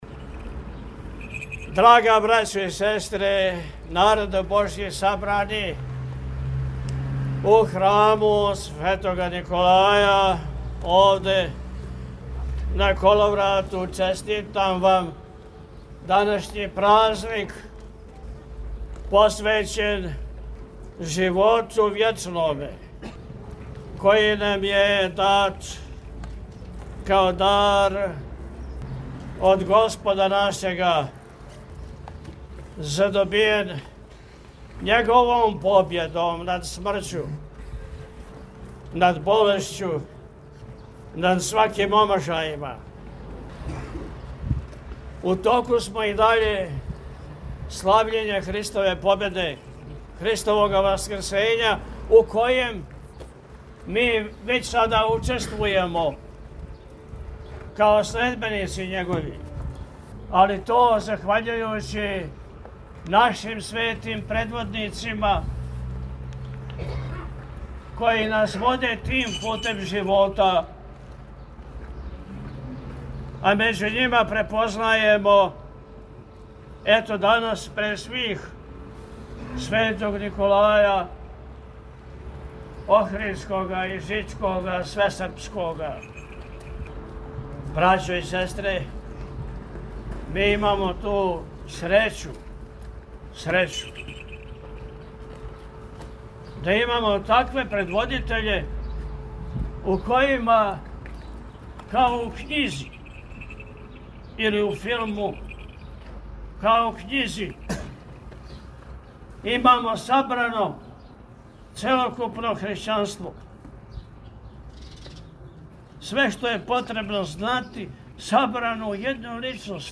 Тим поводом, Његово Високопреосвештенство Архиепископ и Митрополит милешевски г. Атанасије служио је Свету архијерејску Литургију у коловратском храму.
Честитајући славу, Високопреосвећени је у пастирској беседи поред осталог, рекао: – Данашњи празник посвећен је Животу Вечноме, који нам је дат као дар од Господа нашега, задобијен Његовом победом над смрћу, над болешћу, над сваким омашајима.